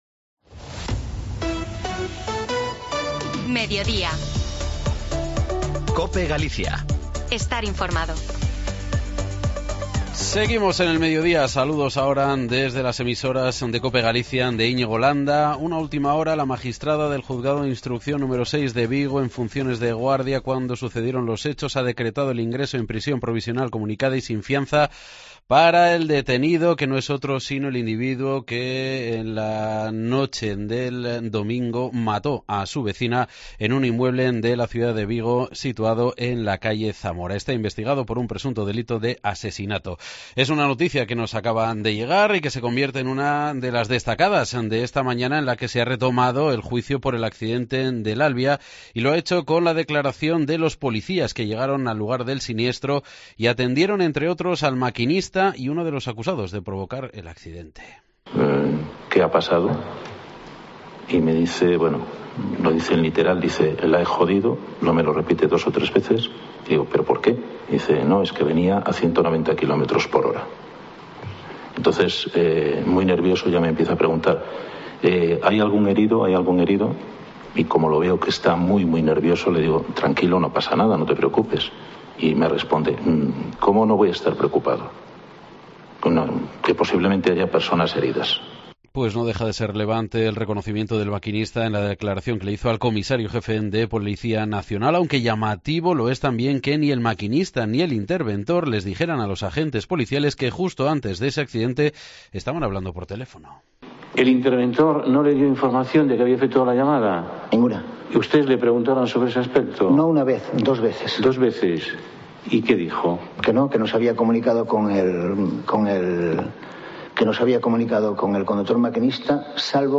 entrevista "surrealista"